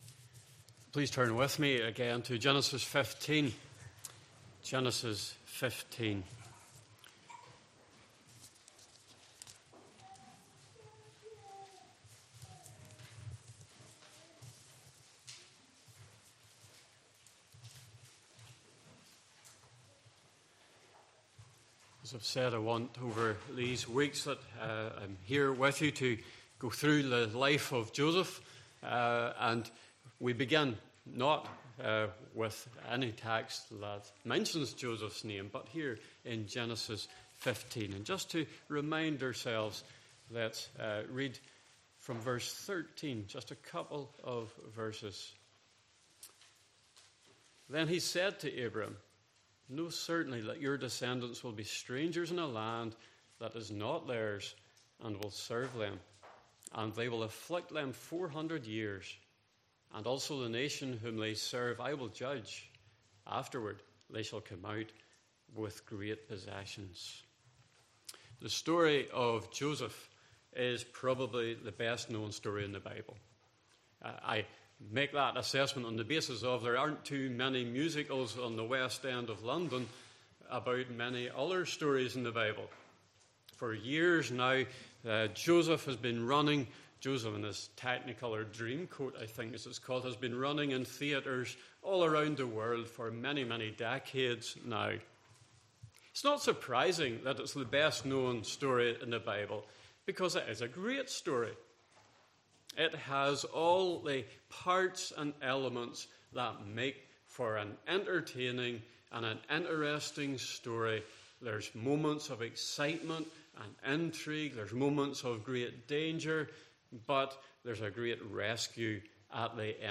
Passage: Genesis 15:1-21 Service Type: Morning Service